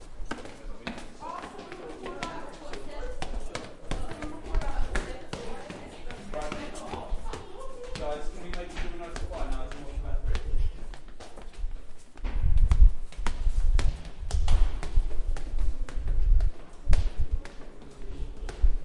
玩球游戏的人
描述：这是人们在柏油碎石路面上玩球类游戏的短暂环境记录。
Tag: 播放 游戏 投球 公园 篮球